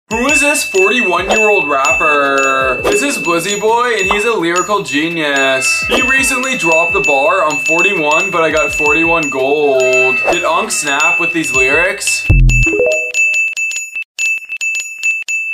are there enough dings?